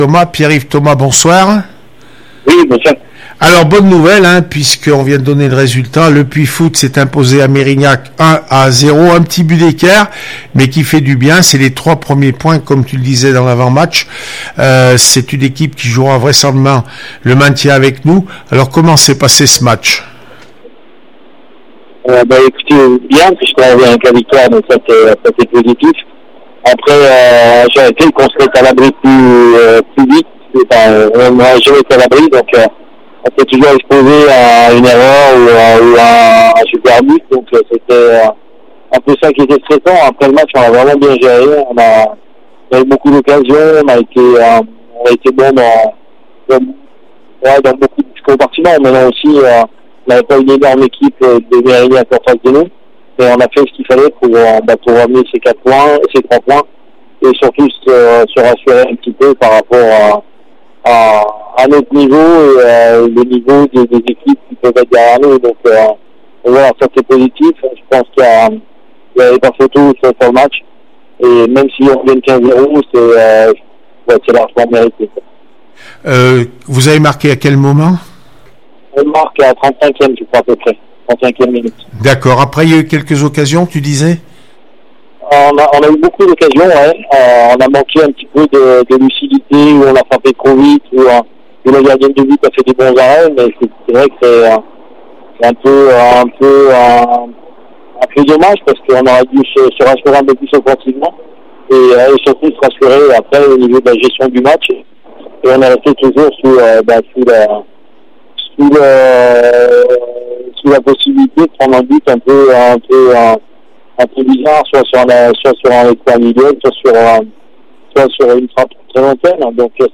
28 septembre 2020   1 - Sport, 1 - Vos interviews, 2 - Infos en Bref   No comments
division 2 féminine merignac 0-1 le puy foot 43 reac apres match 280920